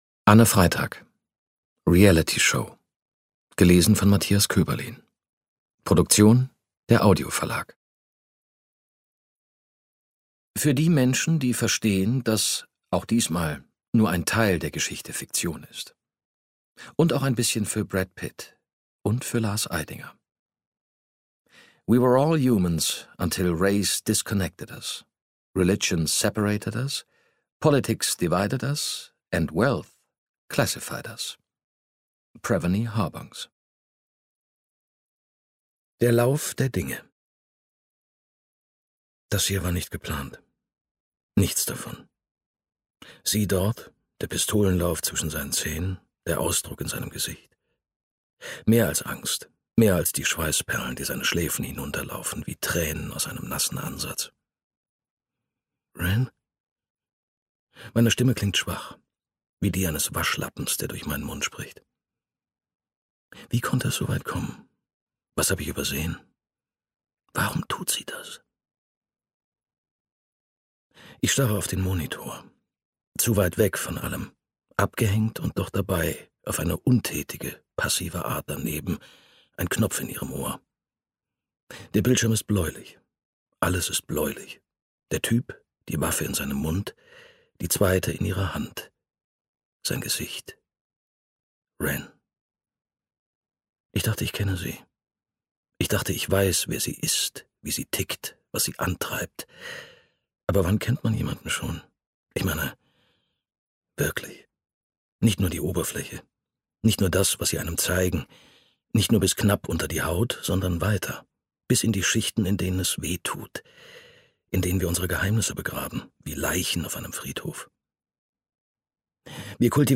Matthias Koeberlin (Sprecher)
2021 | Ungekürzte Lesung